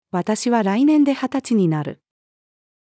私は来年で二十歳になる。-female.mp3